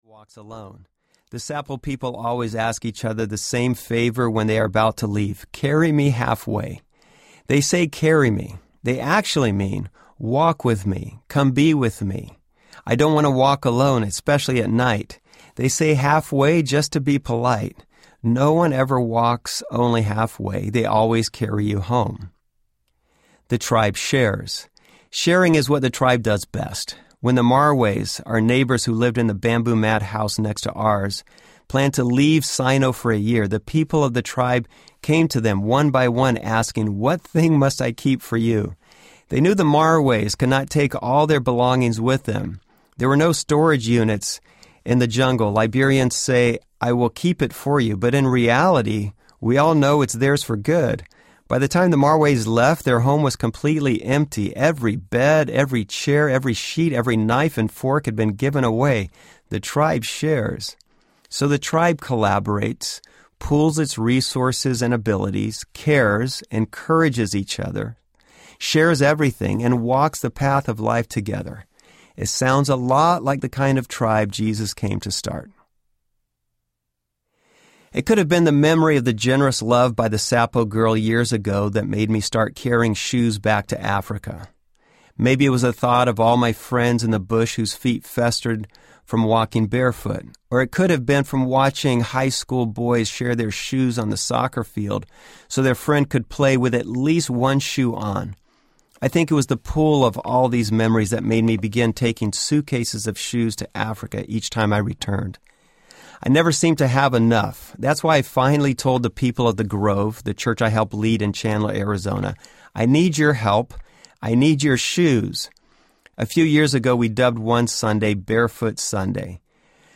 Barefoot Tribe Audiobook
4.45 Hrs. – Unabridged